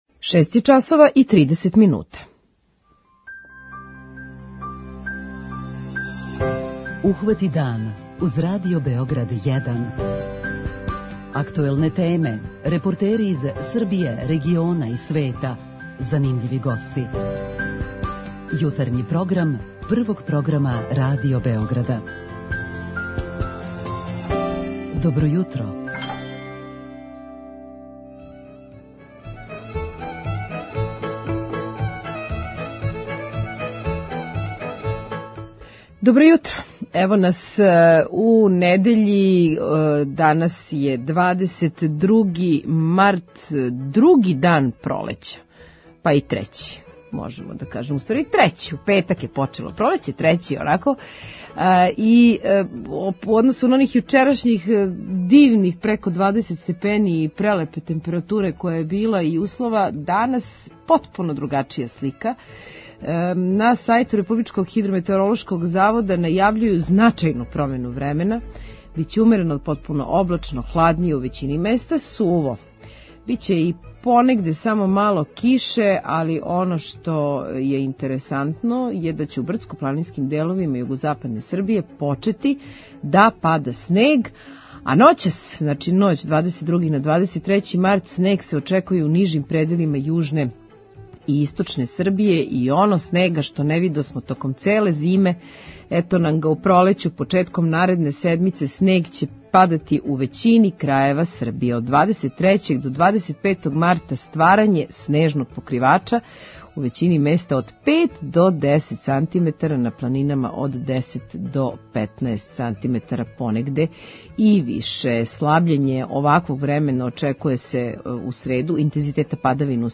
Од овога јутра почеле су да раде продавнице прехрамбене робе за наше најстарије грађане који ће на овај начин моћи да се снабдеју и изађу из својих домова. Од надлежних ћемо чути како је све организовано, а репортер на терену пренеће нам како све функционише.